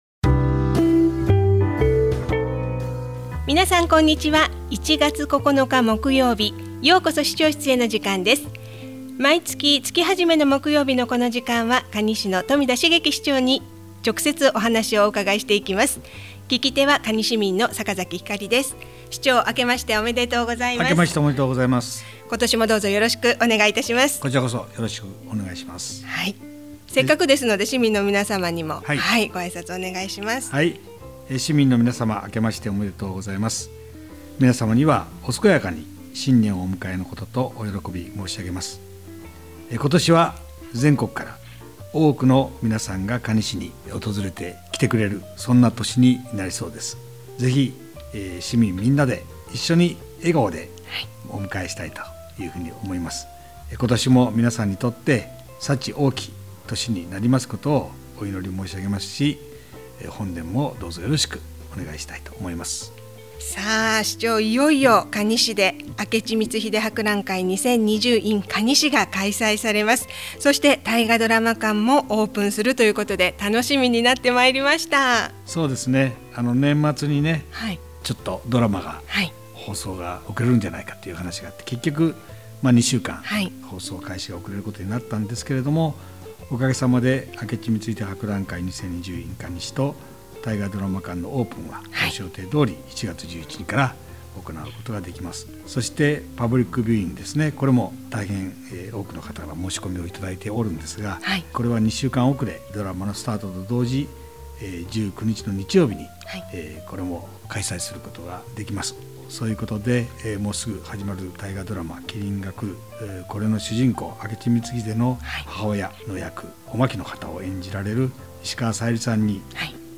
市長室へ 2020-01-09 | ようこそ市長室へ 可児市長「ようこそ 市長室へ」 この番組は、可児市長室へ直接伺って、まちづくりの課題、魅力ある地域、 市政情報などを中心に、新鮮な情報を冨田市長から、生の声で皆様にお届けする番組です。